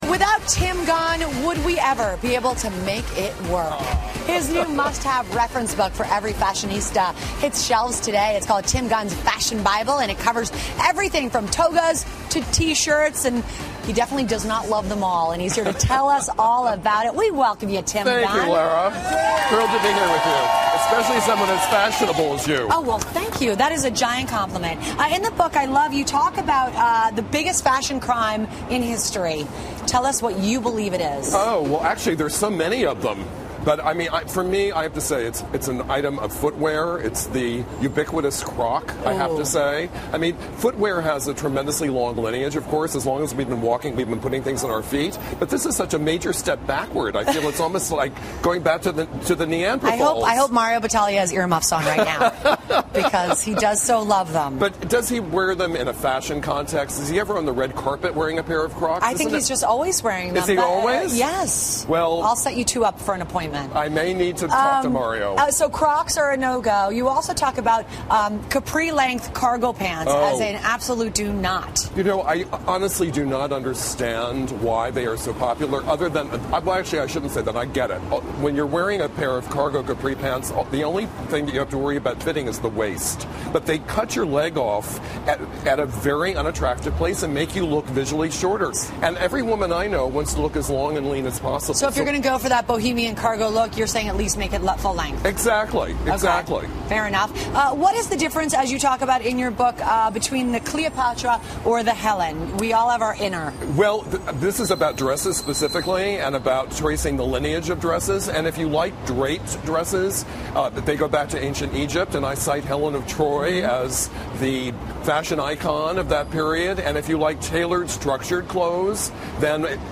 访谈录 2012-09-18&09-20 时尚专家蒂姆·甘恩谈服饰搭配 听力文件下载—在线英语听力室